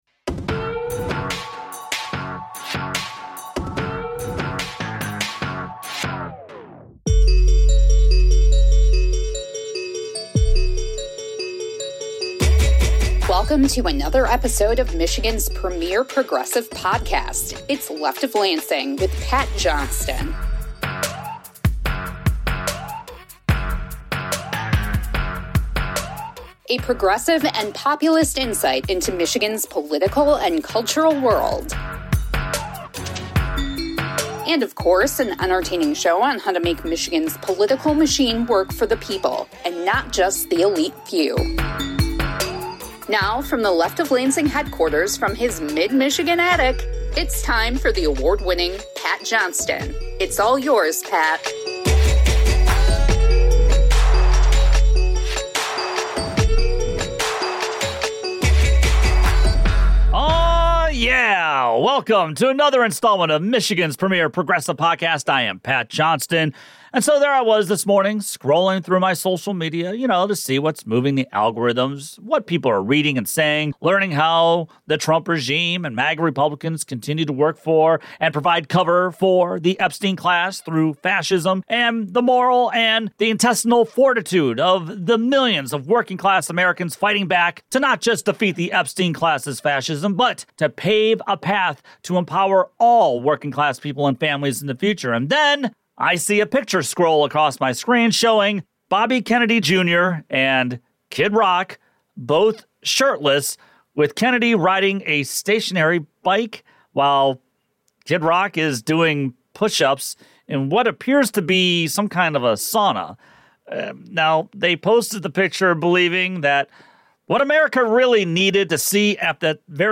Stevens is the illustration of why Democratic base voters are frustrated with Democrats. 16:41-38:30: Abdul El-Sayed Interview